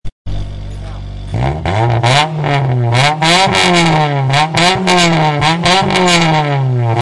Listen to this roar
car-engine-revving-94831.mp3